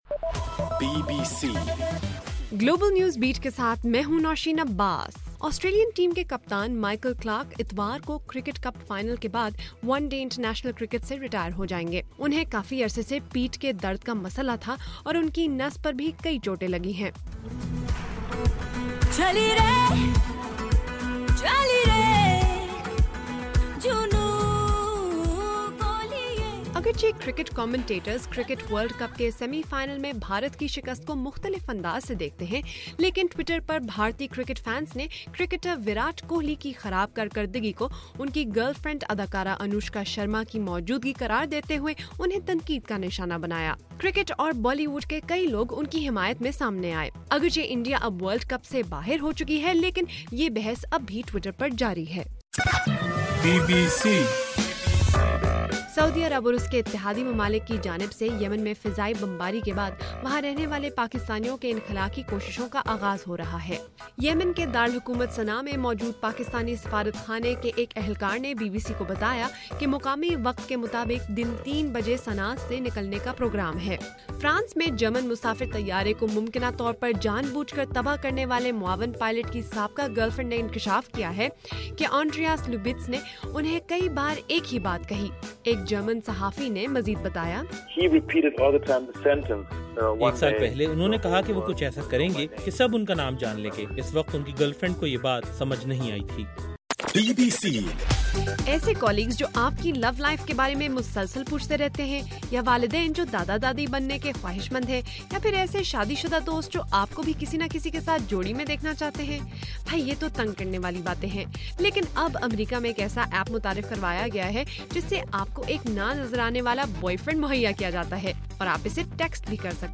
مارچ 28: رات 9 بجے کا گلوبل نیوز بیٹ بُلیٹن